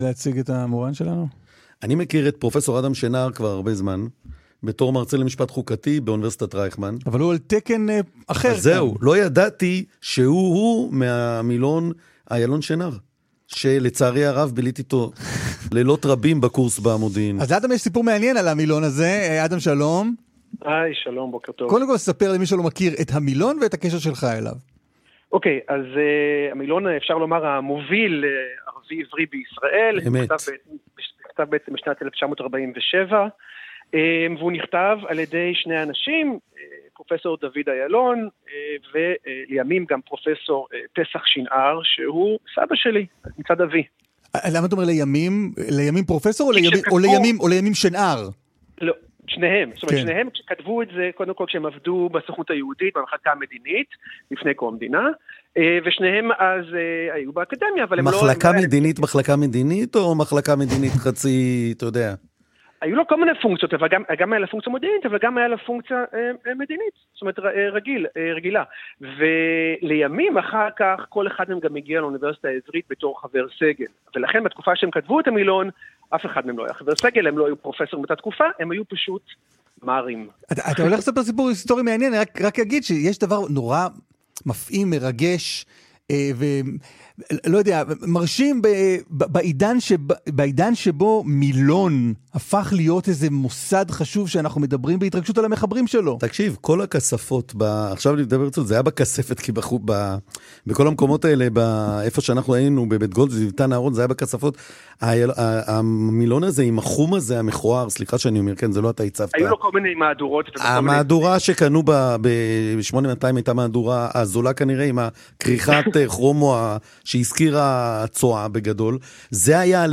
ראיון